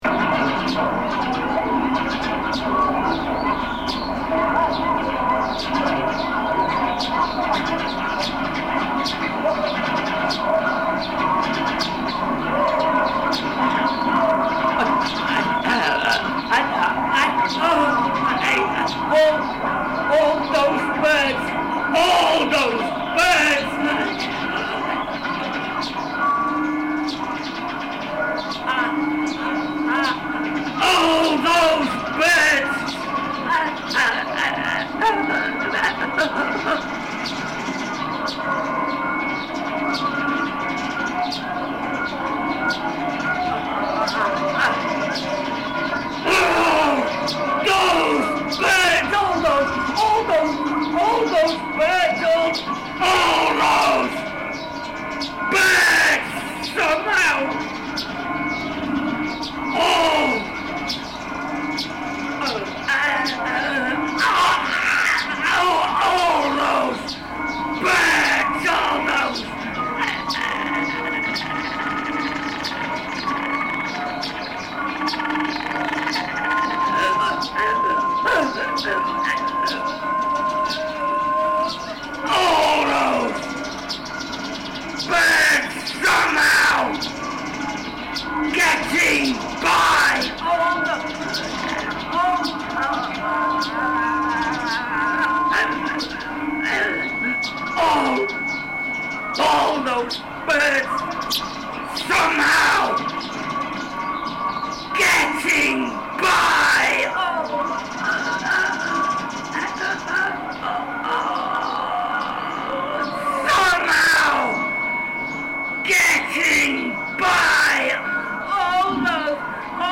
Lanzhou City soundscape reimagined